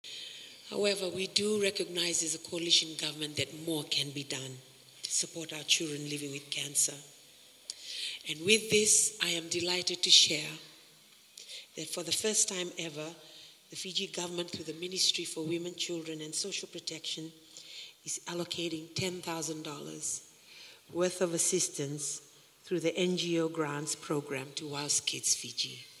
[Minister for Women and Children Lynda Tabuya]
Tabuya launched International Childhood Cancer Awareness Month today at the Api Tonga Community Centre in Suva.